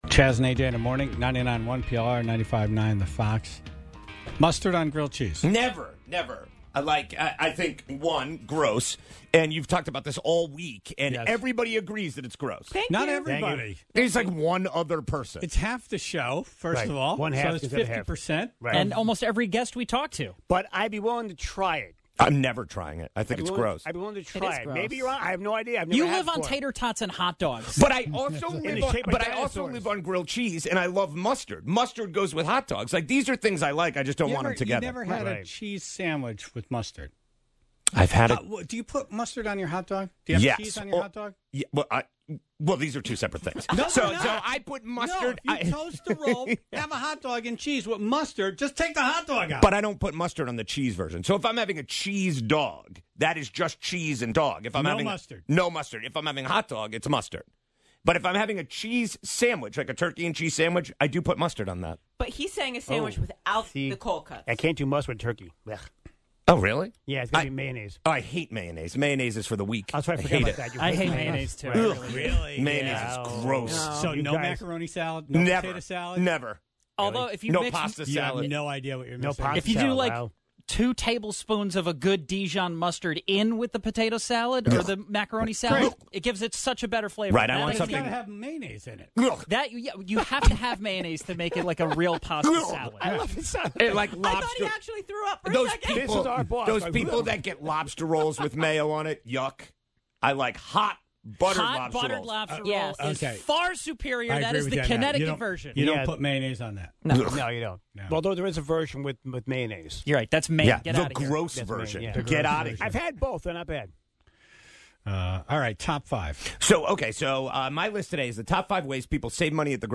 Here, he yells about the 5 nonsense grocery saving tips that he refuses to do.